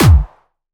Jumpstyle Kick 7
14 AttackNoize.wav